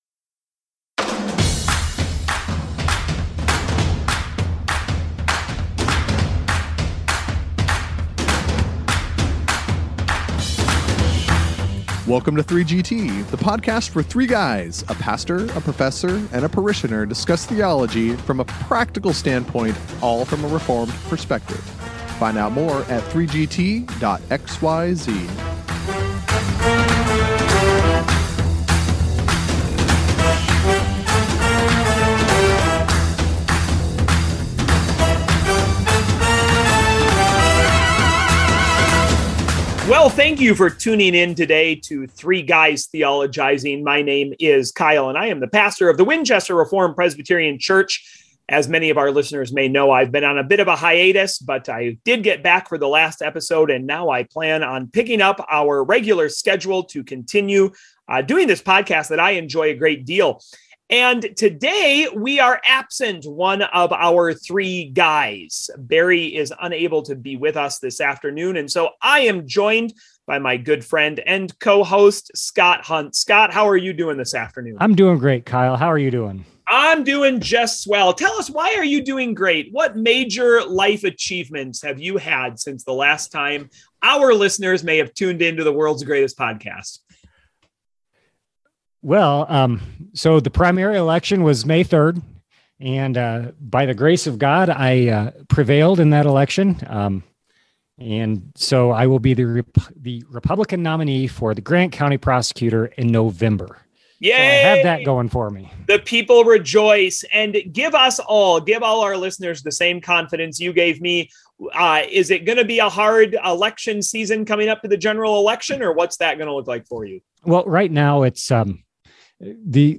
Punctuated with their typical liveliness, the boys make this episode one you will want to be sure to listen to!